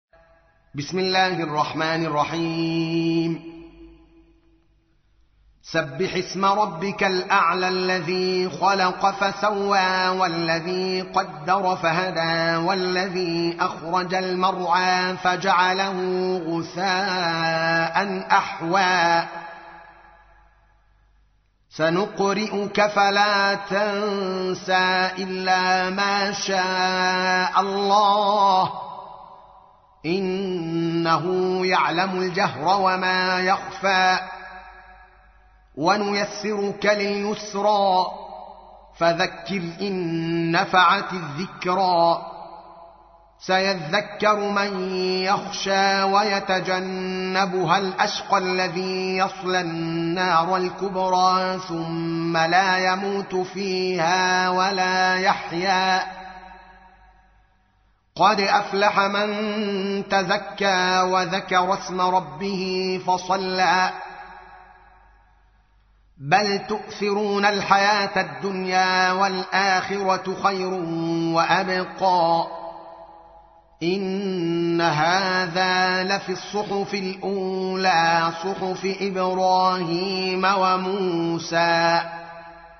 تحميل : 87. سورة الأعلى / القارئ الدوكالي محمد العالم / القرآن الكريم / موقع يا حسين